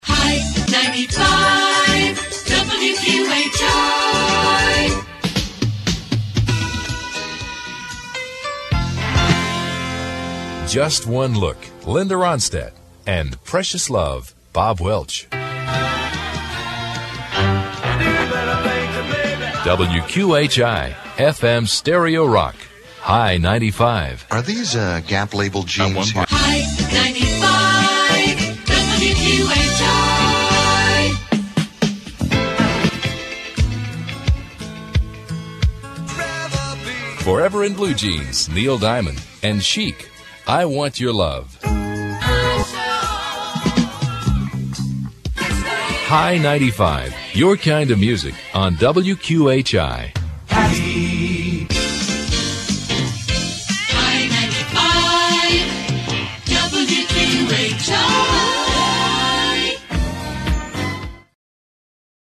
This simulated WQHI aircheck was part of a TM demo for the Stereo Rock format.